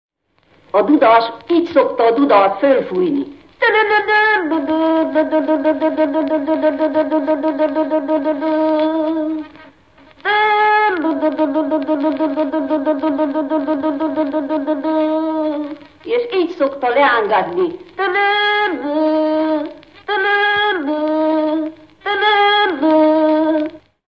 Műfaj Dudautánzás
Helység Szany
Megye Sopron